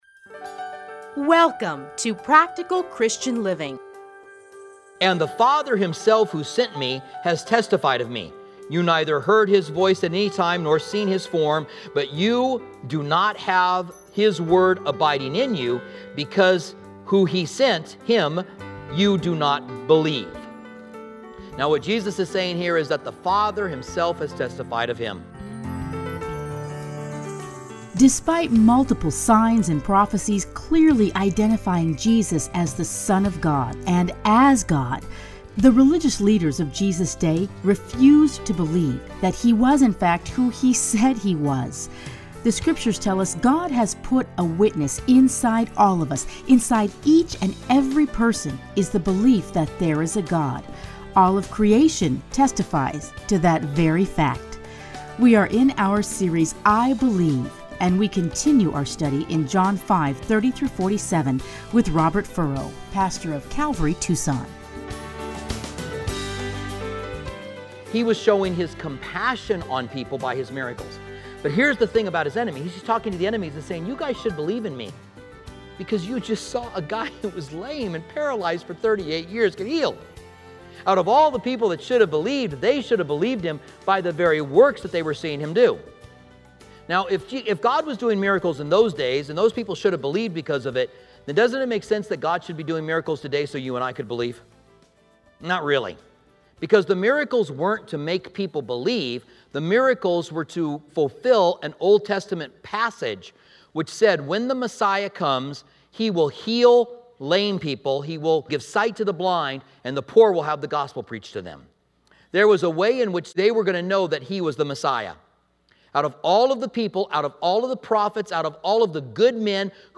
teachings are edited into 30-minute radio programs titled Practical Christian Living.